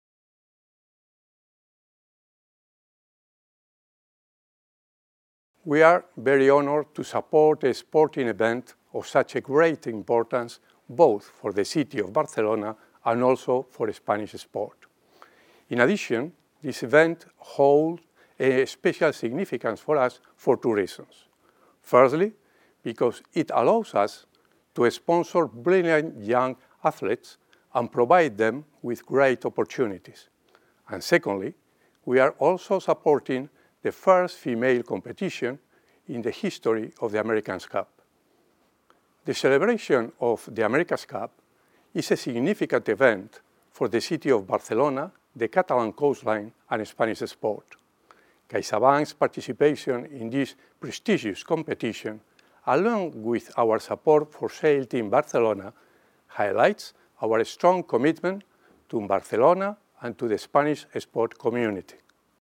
Quotes from CaixaBank’s president, Juan Ignacio Goirigolzarri
Audio - Quotes from CaixaBank’s president